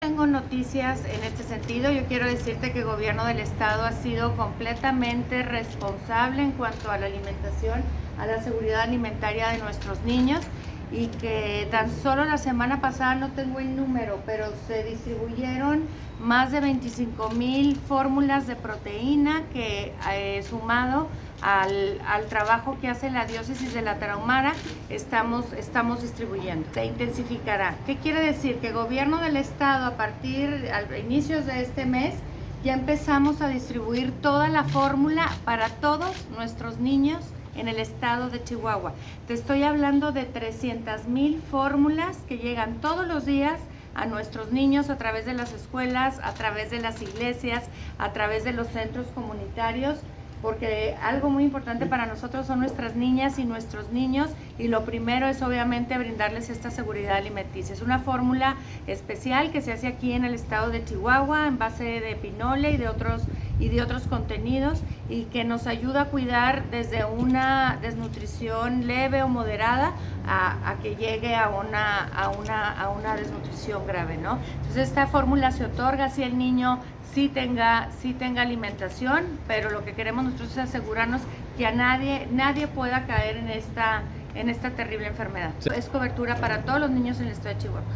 Gobernadora-sobre-apoyos-alimenticios-para-menores-1.mp3